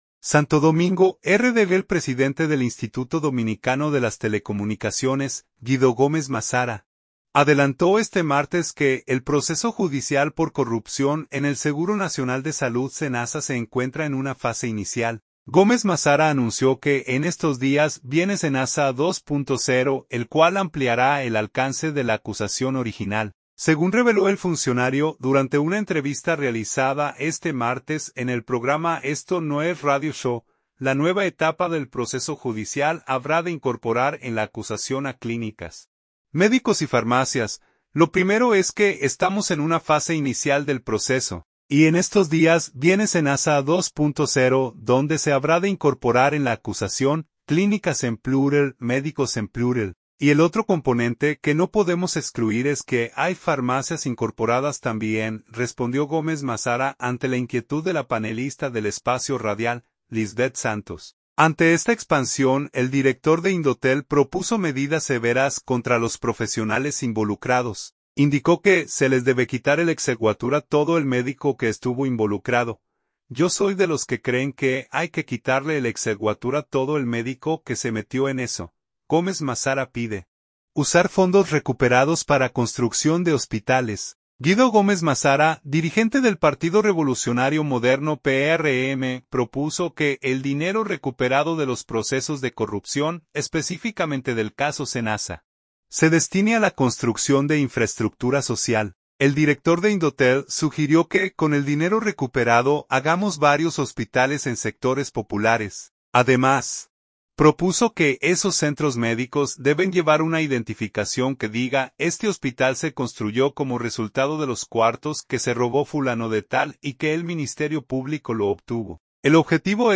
Según reveló el funcionario durante una entrevista realizada este martes en el programa Esto No es Radio Show, la nueva etapa del proceso judicial habrá de incorporar en la acusación a clínicas, médicos y farmacias.